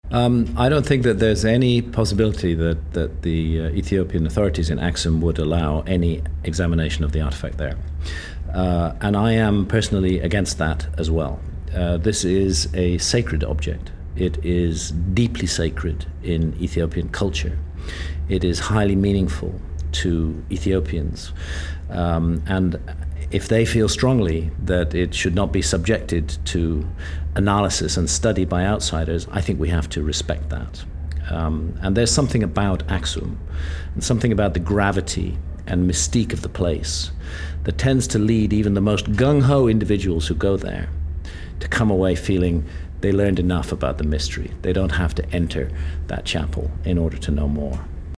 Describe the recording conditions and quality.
Then as the chairs were being folded and with only minutes before leaving for the airport, he graciously agreed to sit down with me and field a few questions: